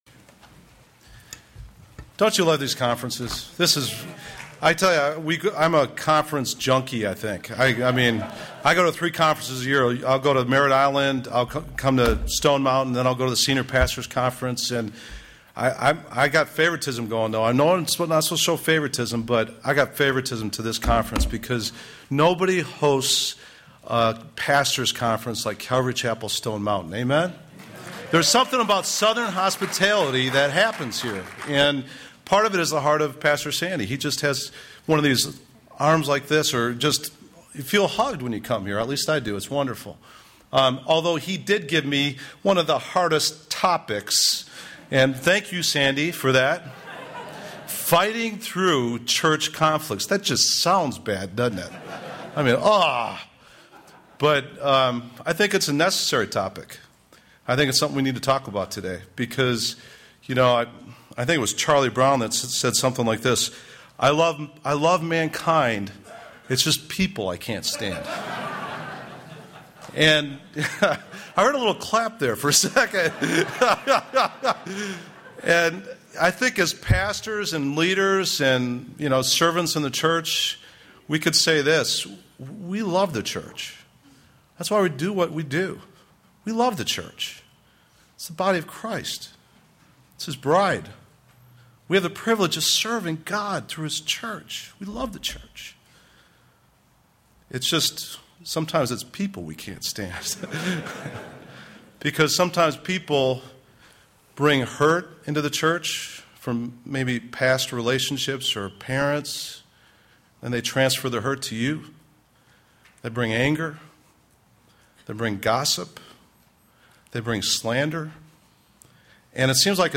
2010 Home » Sermons » Session 2 Share Facebook Twitter LinkedIn Email Topics